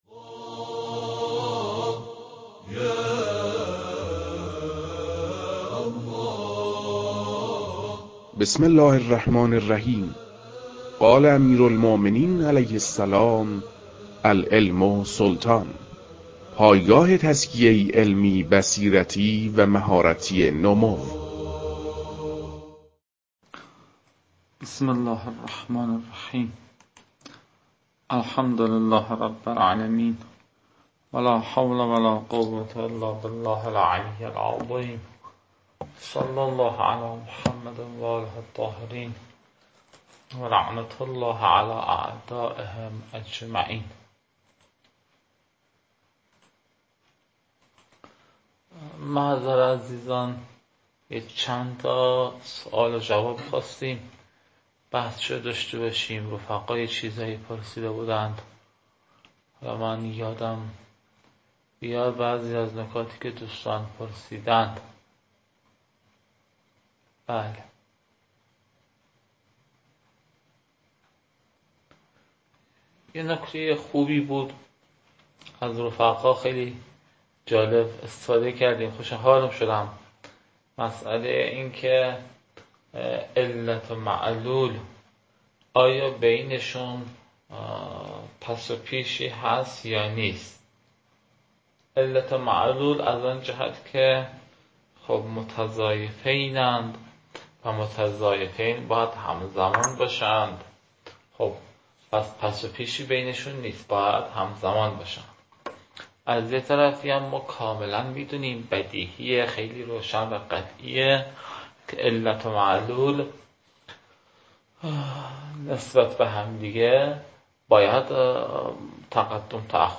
قابل ذکر است که این صوتها، متعلق به محدوده نیمسال اول است.